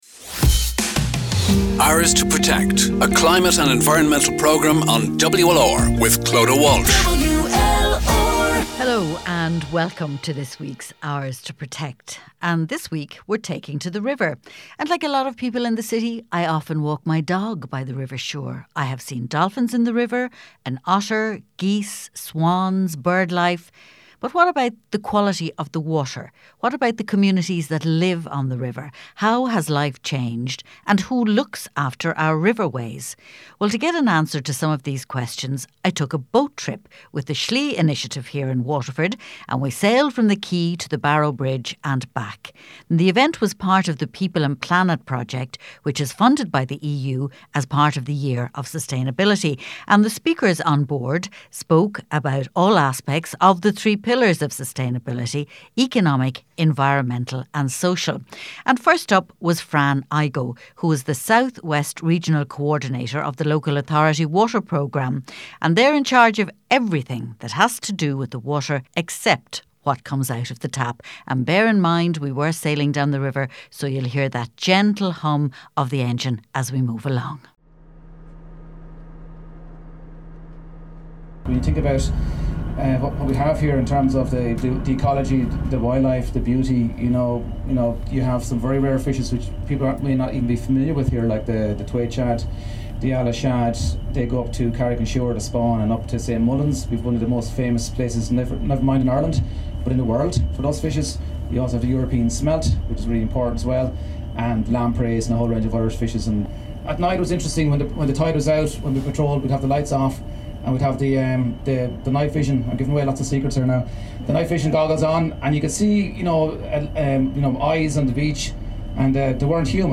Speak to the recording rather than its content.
She heard from interested groups on how our waterways can be harnessed to drive positive change